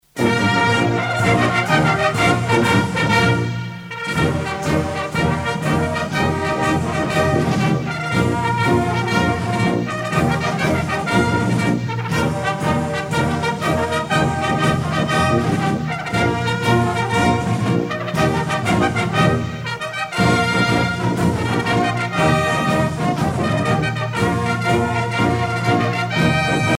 à marcher
militaire
Pièce musicale éditée